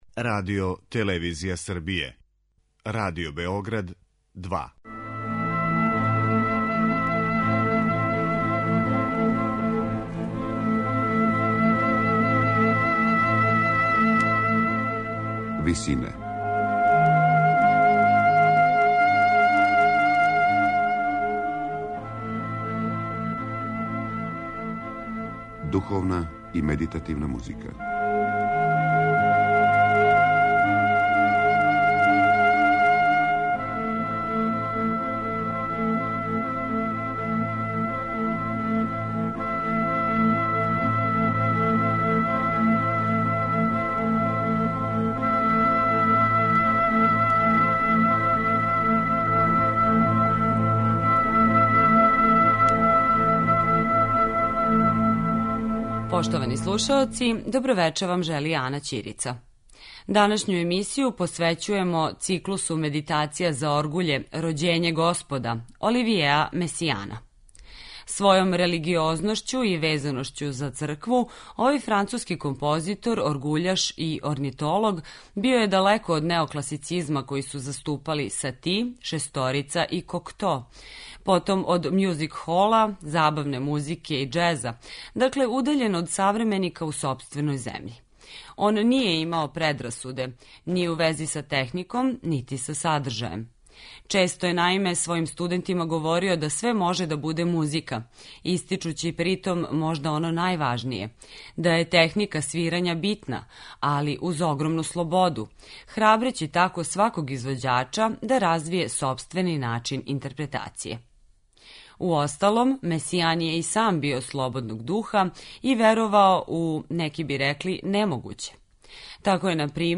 Емисија духовне музике